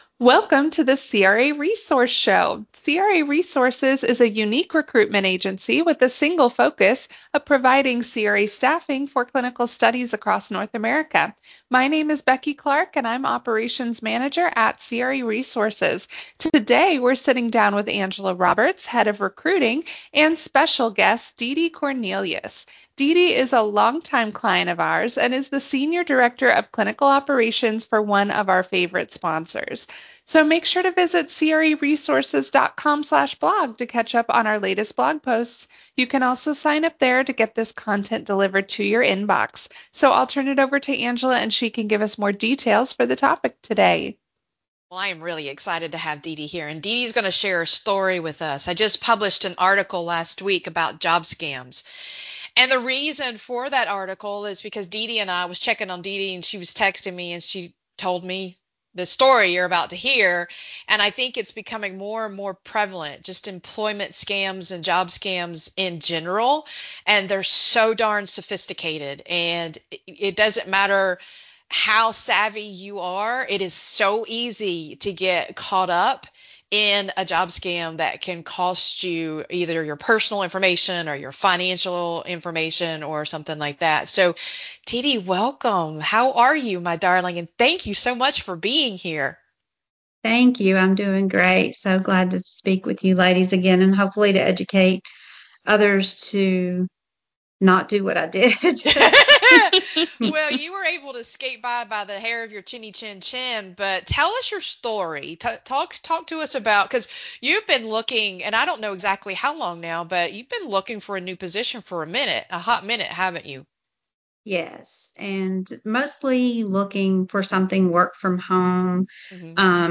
Welcome to today’s podcast episode, where we have a special conversation planned.